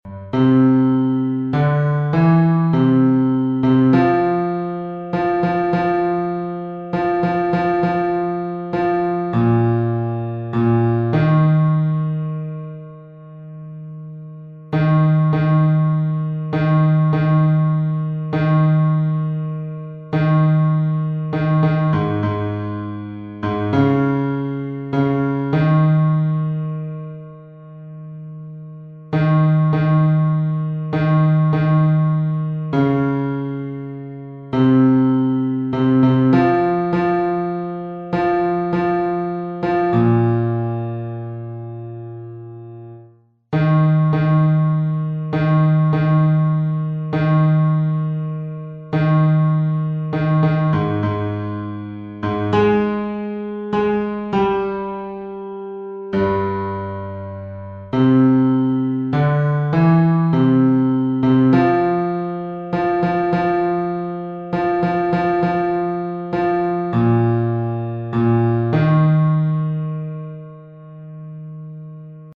伴奏
男低